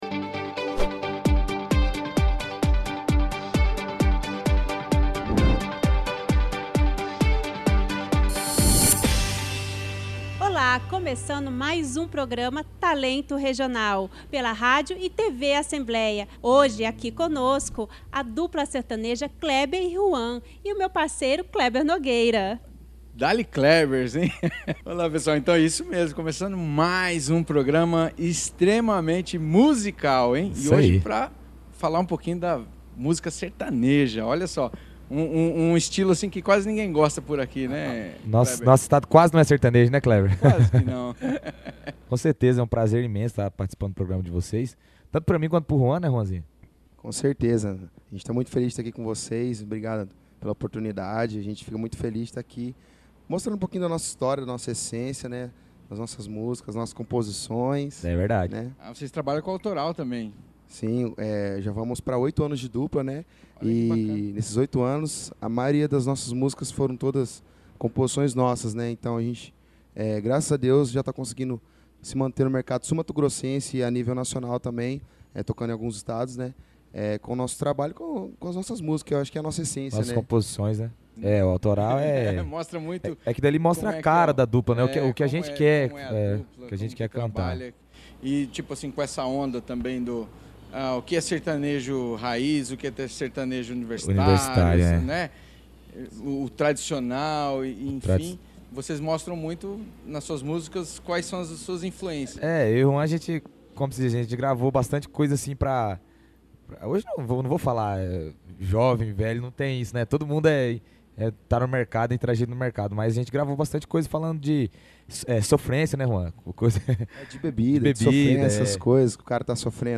O programa está na grade diária de programação da TV e Rádio da ALEMS.